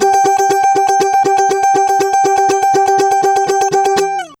120FUNKY11.wav